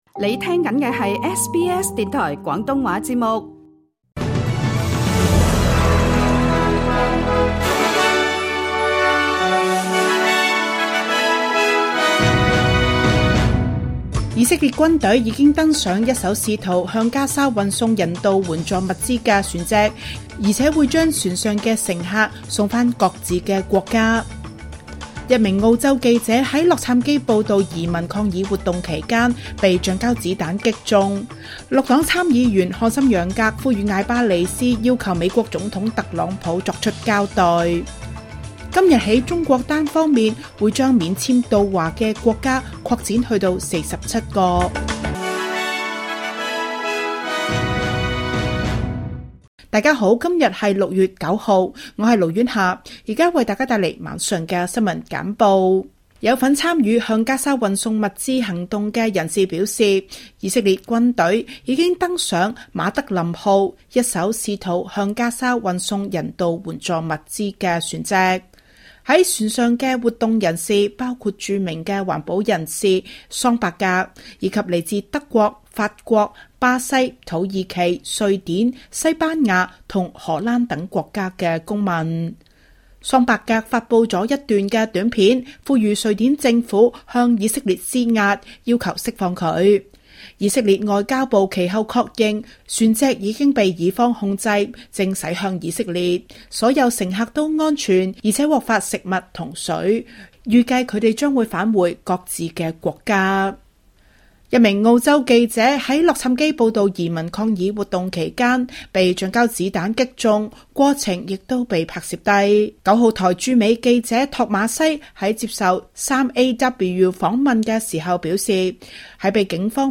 SBS晚間新聞（2025年6月9日）
請收聽本台為大家準備的每日重點新聞簡報。